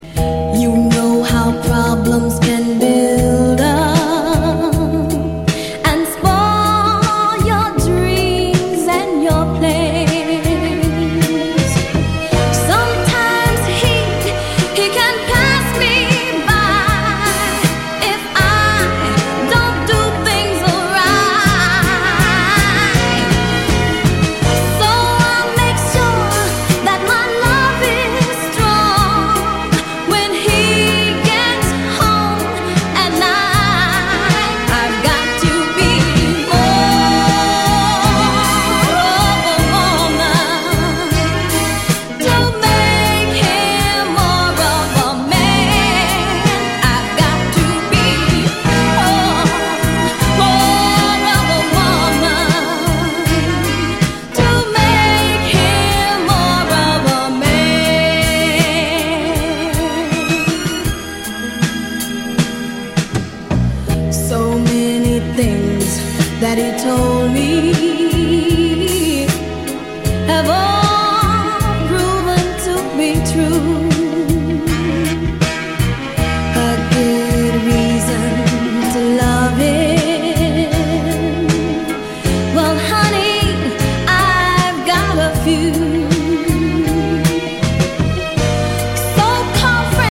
】1977年リリースのオリジナルは4,5万は下らない激レア・ファンキー・ソウル〜ディスコ・アルバムが正規復刻！